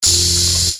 sound / weapons / blade1.ogg
blade1.ogg